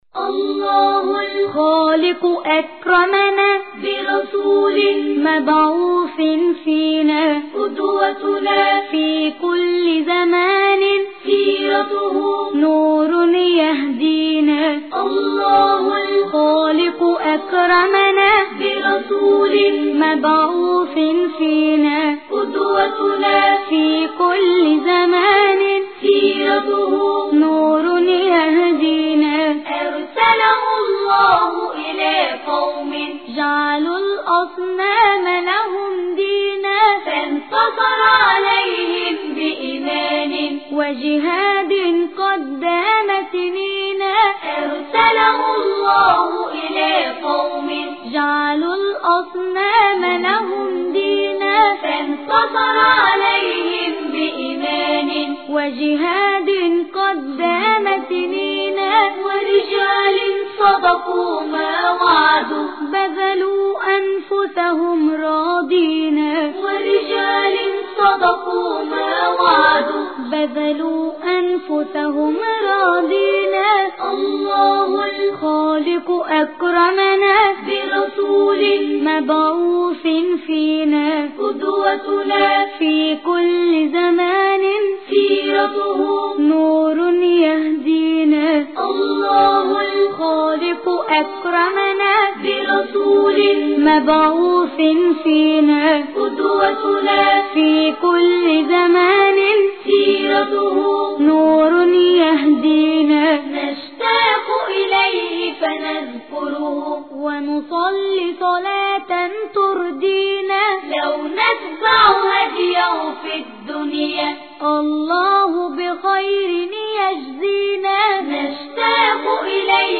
تواشیج